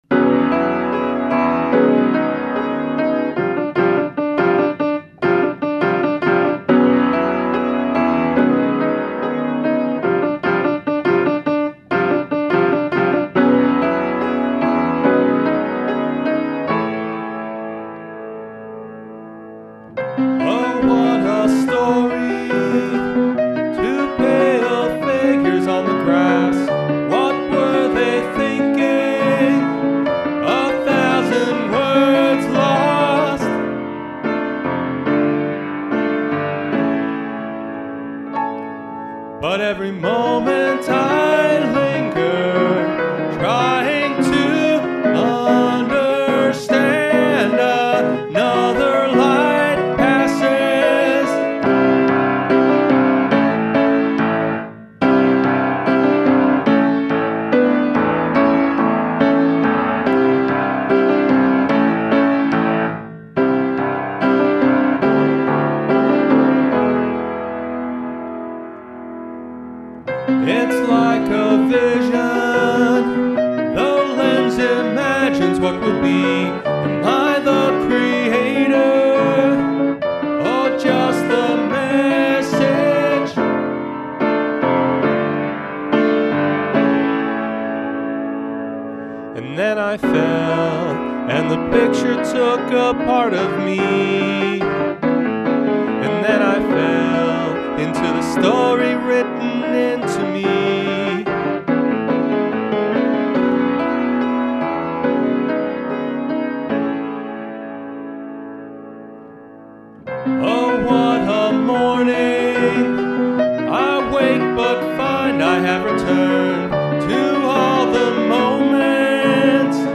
rock opera
piano and voice